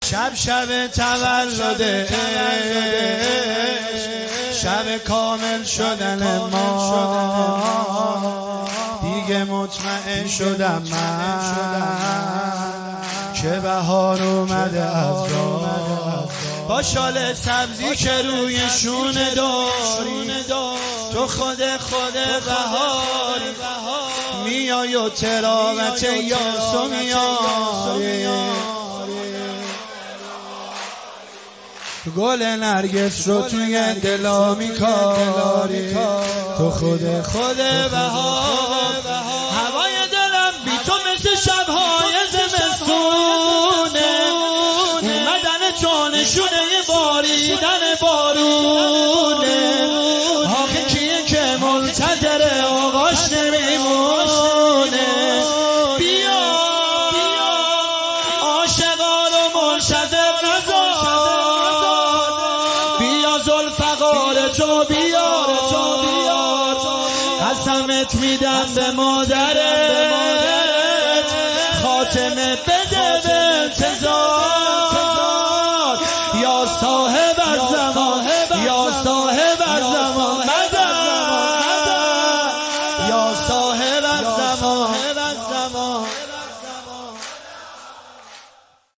نماهنگ زیبا و دلنشین